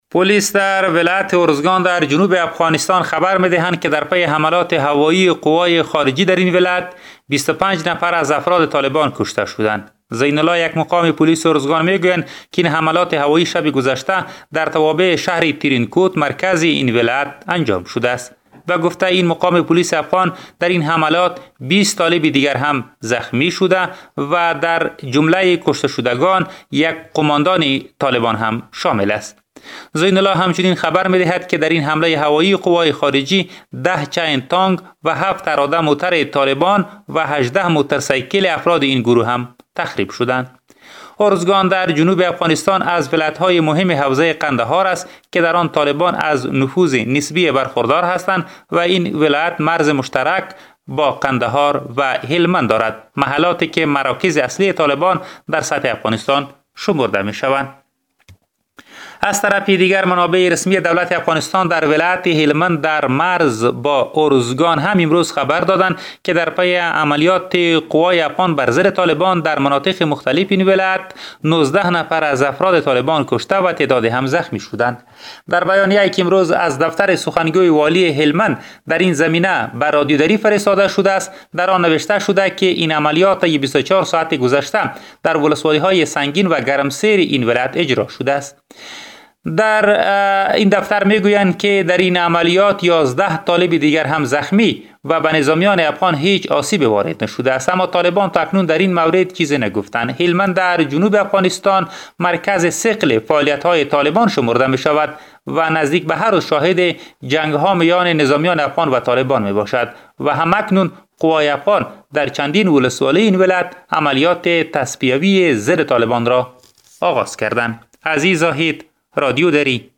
به گزارش خبرنگار رادیو دری، پلیس در ولایت ارزگان در جنوب افغانستان میگوید که در پی حملات هوایی نیروهای خارجی در این ولایت بیست و پنج نفر از افراد طالبان کشته شده اند.